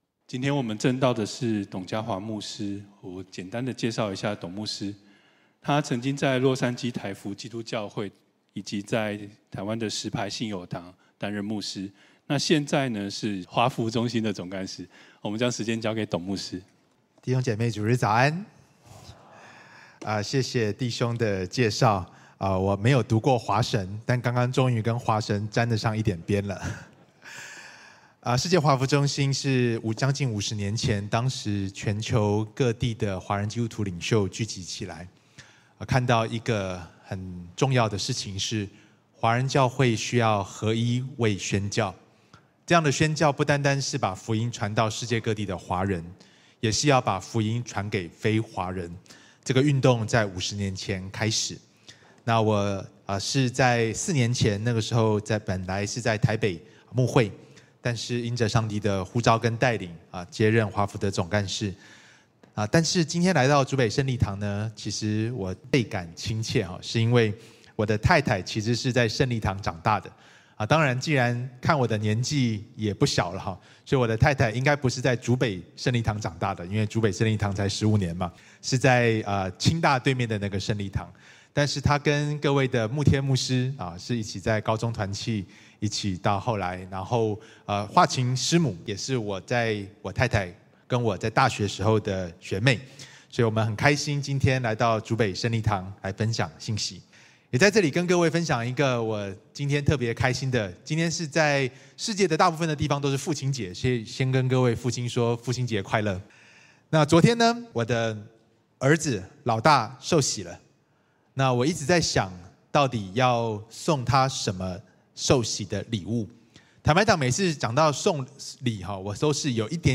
Posted in 主日信息